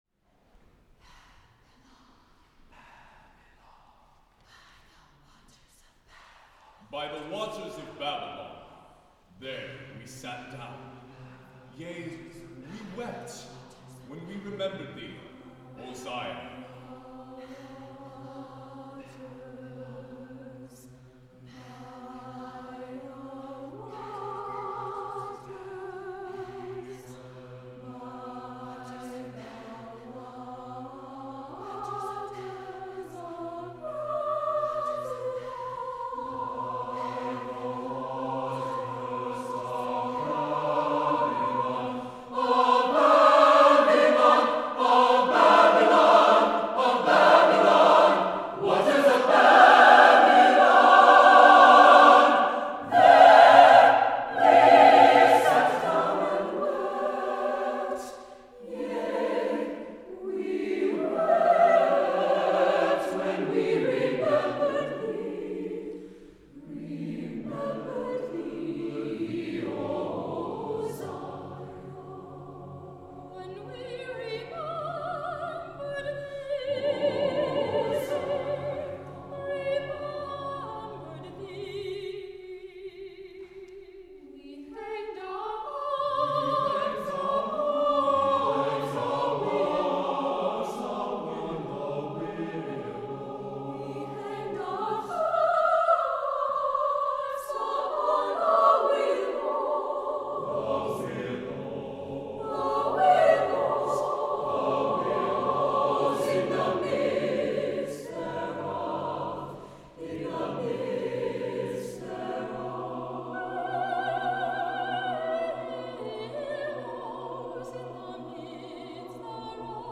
Voicing: SATB with soprano solo and speaker